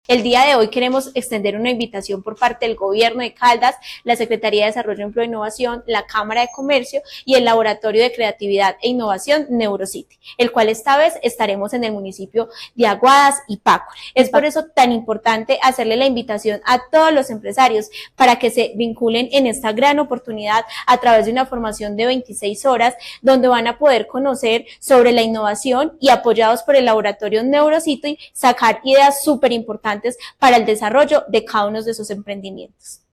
Tania Echeverry, secretaria de Desarrollo, Empleo e Innovación de Caldas.
Tania-Echeverry-secretaria-de-Desarrollo-Empleo-e-Innovacion-de-Caldas.mp3